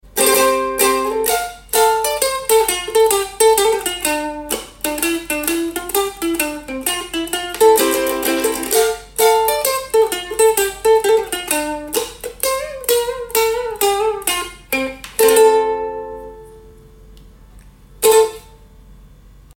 Ukulele Cover